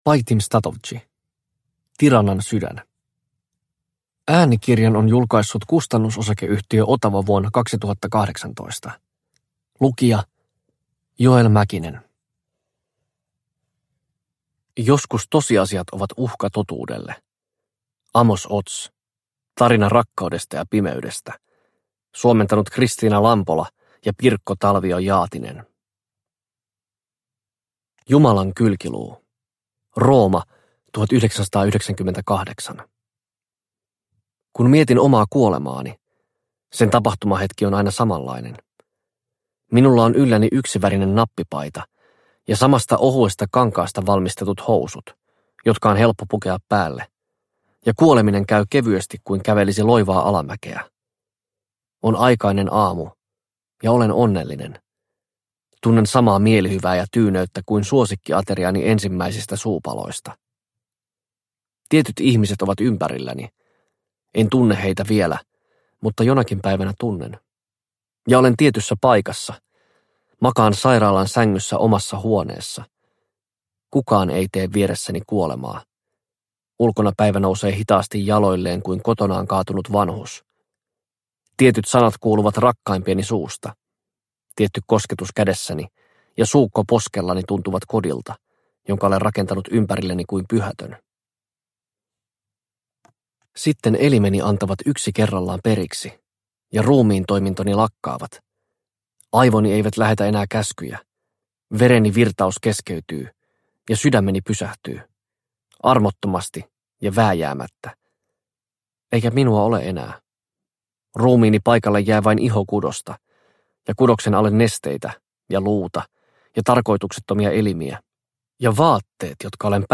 Tiranan sydän – Ljudbok – Laddas ner